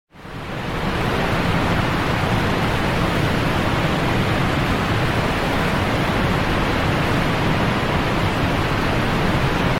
Nature Sound Effects 3 Waterfall
Category: Sound FX   Right: Personal